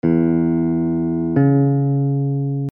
In the diagrams below, we are jumping from a note on an open string (any string will do) to another note on the same string.
Major Sixth = 4 ½ steps
major-6th.mp3